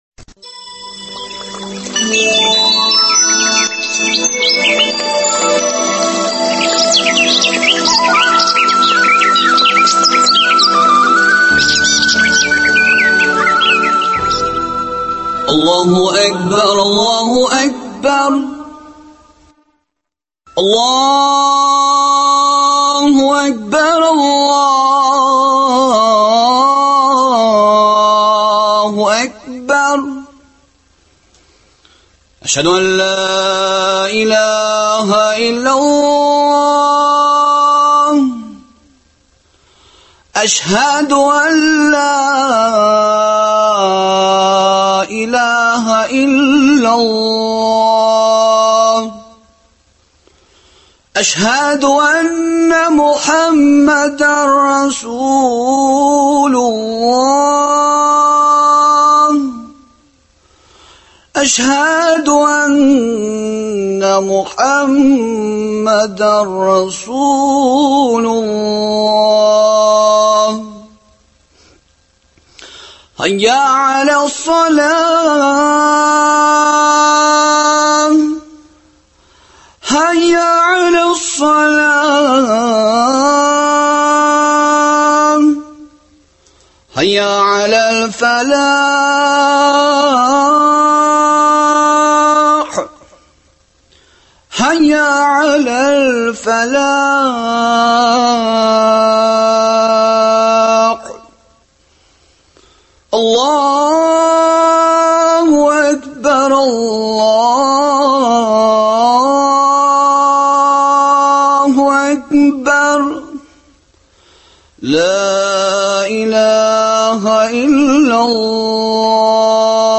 бу темага әңгәмә тәкъдим итәбез.